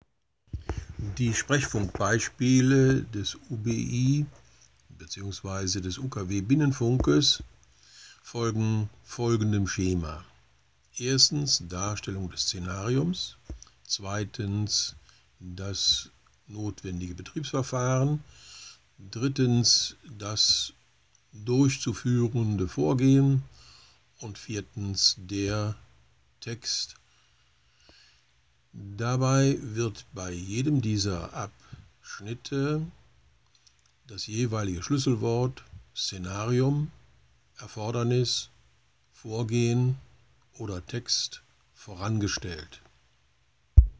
UBI - Sprechfunkbeispiele
Vor den eigentlichen Funksprüchen, gleichgültig ob Not-, Dringlichkeits- oder Sicherheitsverkehr, wird das zugehörige Szenarium dargestellt sowie die erforderlichen Maßnahmen und das Vorgehen benannt, um ein größeres Verständniss für den Funkspruch zu erreichen.